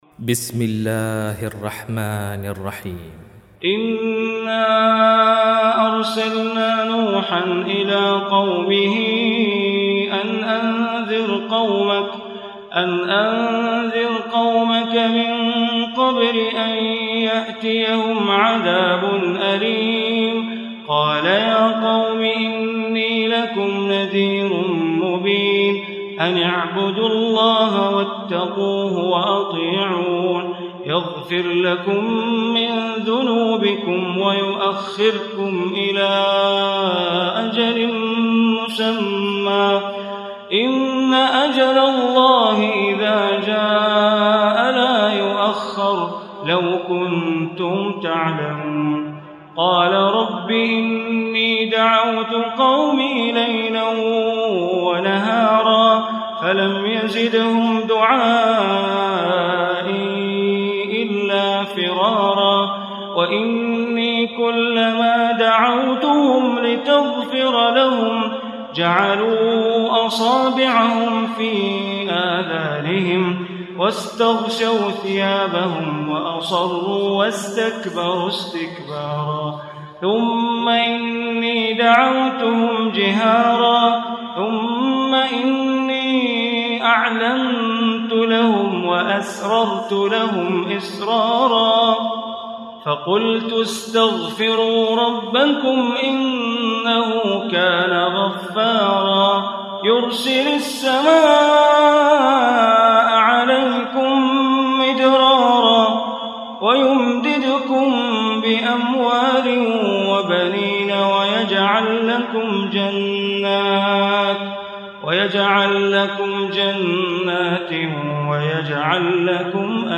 Surah Nuh Recitation by Sheikh Bandar Baleela